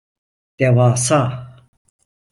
devasa a 🐌 Betekenis Concepten Synoniemen Vertalingen Notes Extra tools (Engels) colossal, gigantic Frequentie B2 Uitgesproken als (IPA) /de.vaːˈsaː/ Etymologie (Engels) From Persian دیو (dēw) + آسا (âsâ).